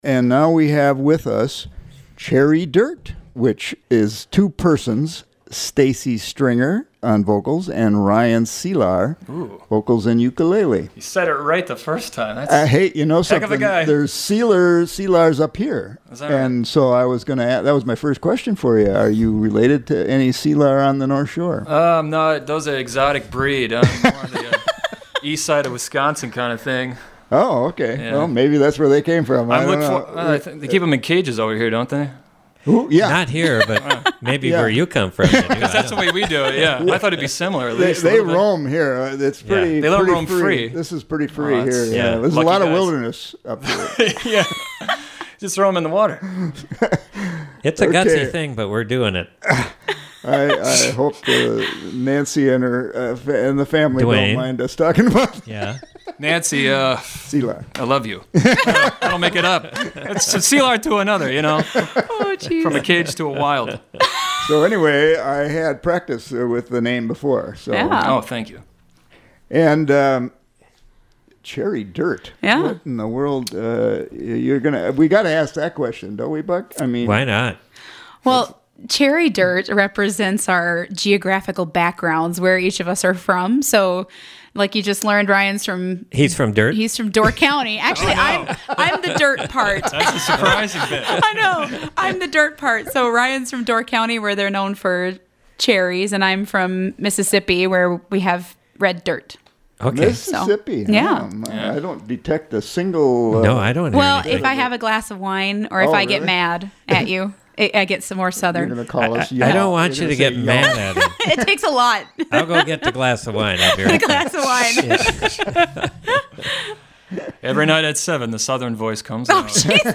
vocals & ukulele), the duo
including some great yodeling